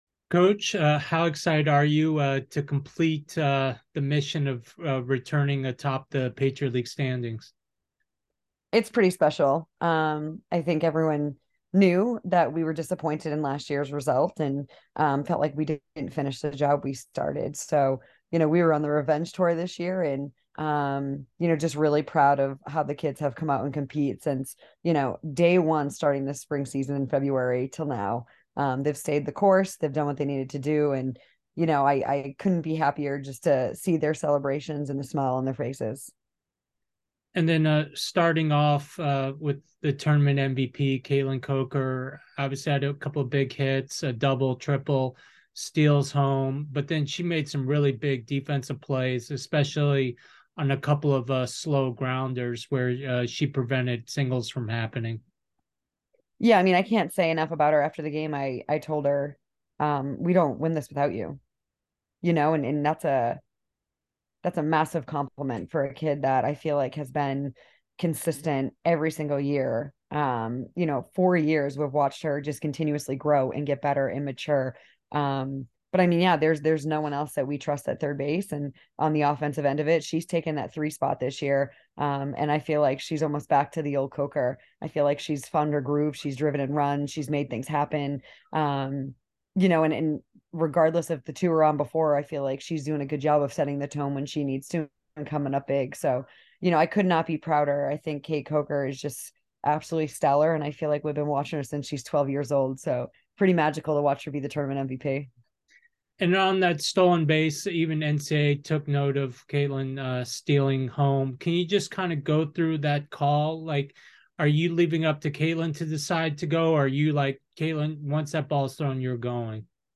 Army Postgame Interview